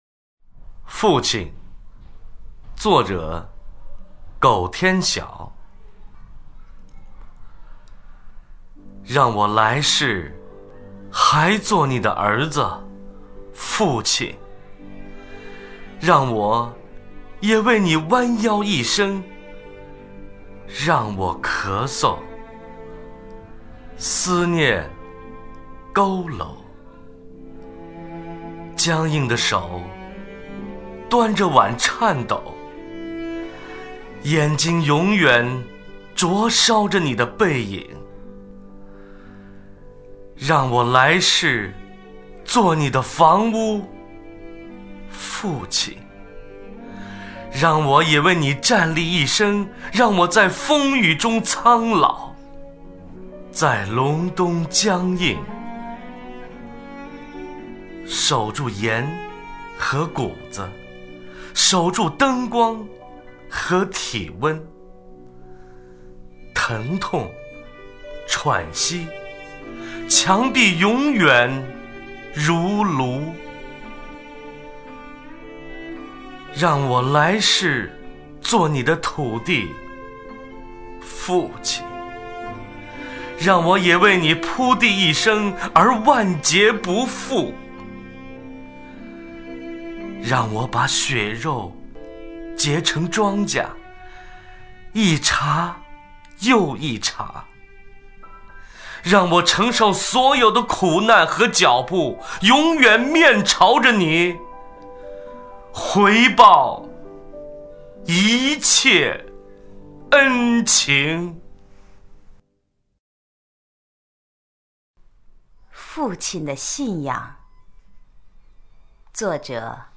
[3/12/2009]配乐诗朗诵 献给父亲的一组诗歌